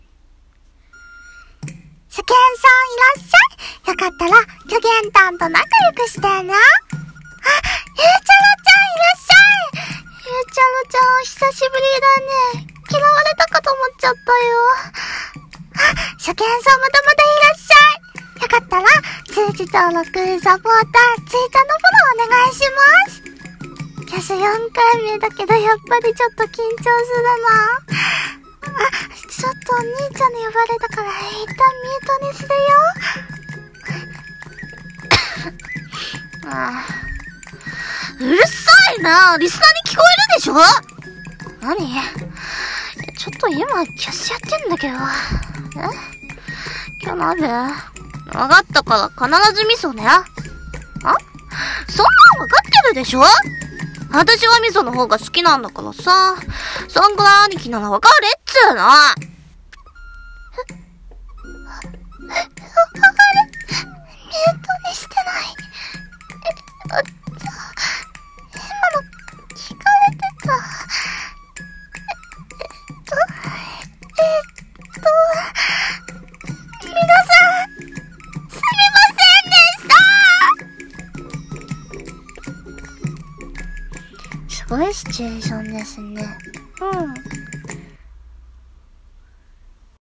【1人声劇】